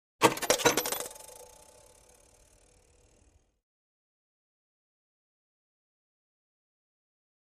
Coin Drop Pay Phone | Sneak On The Lot
Coin Dropping Into Pay Phone Coin Return.
Coin Dropping Into Slot In Pay Phone.